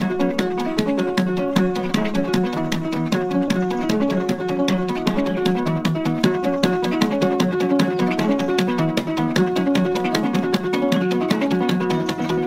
Below is a selection of music loops taken from our field recordings across East Africa.